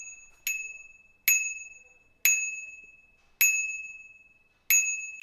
Ringing bicycle ring
bell bike ding dong ring ringing sound effect free sound royalty free Sound Effects